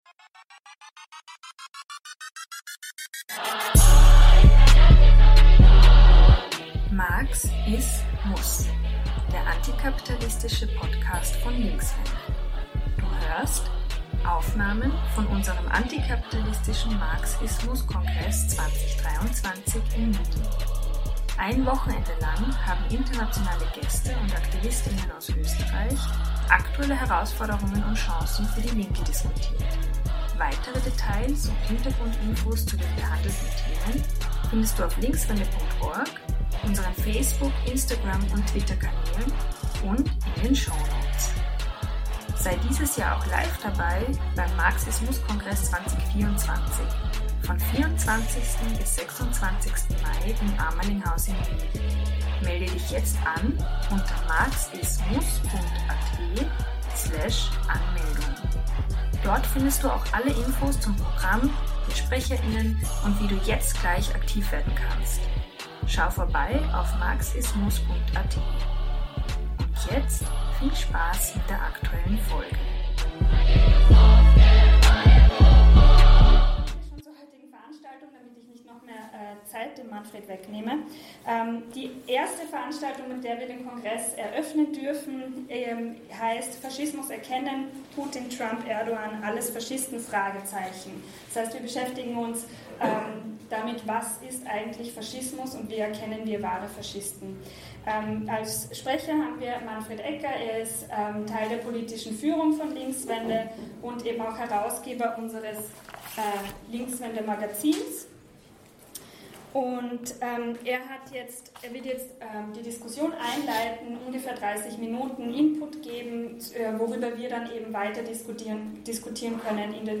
Du hörst den Vortrag „Faschismus erkennen
am MARX IS MUSS Kongress 2023 in Wien.